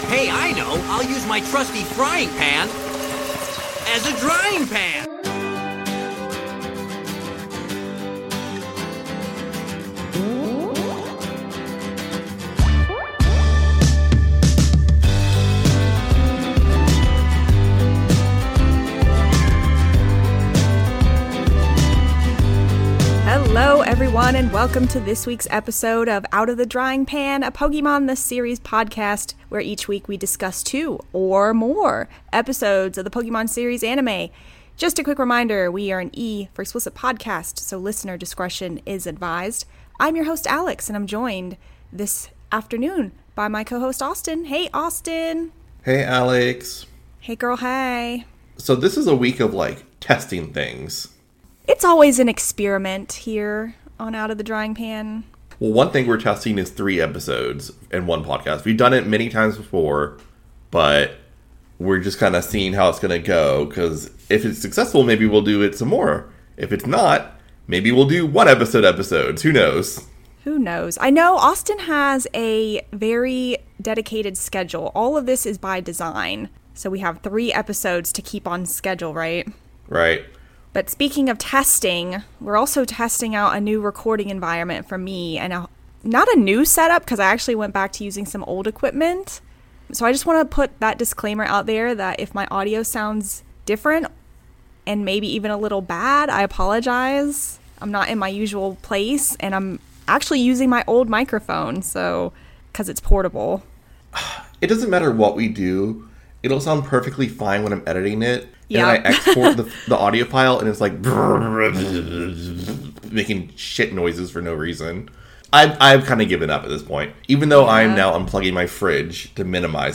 A podcast where a filthy casual Millennial duo looks back at the English dub of Pokémon the Series... and pick it apart relentlessly.